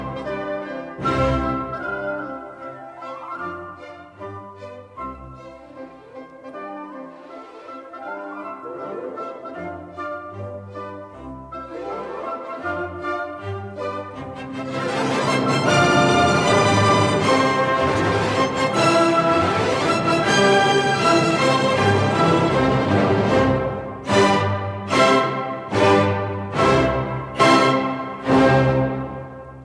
The coda starts with a quiet section, which is in fact a new version in the introduction. A sudden energy is released, music is building up with rising C major scales, leading to the conclusion.